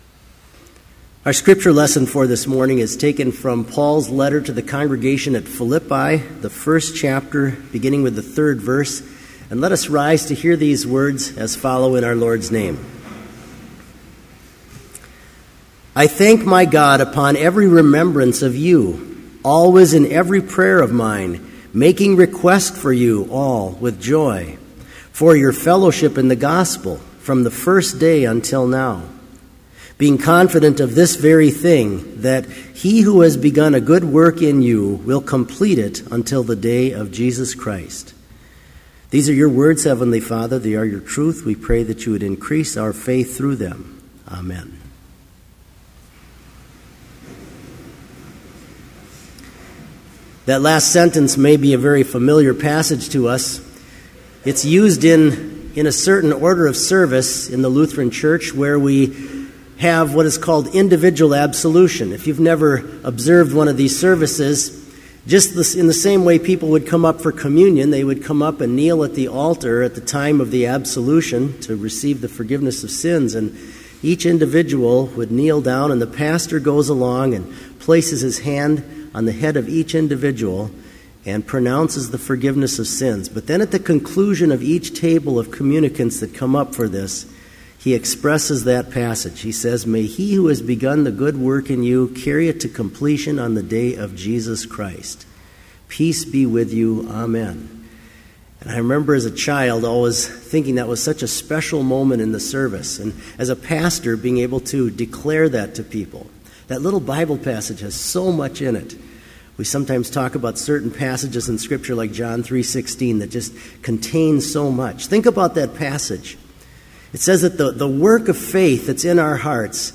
Complete Service
• Prelude
• Homily
Choir: We praise You and acknowledge You, O God, to be the Lord, The Father everlasting, by all the earth adored.
• Postlude
This Chapel Service was held in Trinity Chapel at Bethany Lutheran College on Monday, September 23, 2013, at 10 a.m. Page and hymn numbers are from the Evangelical Lutheran Hymnary.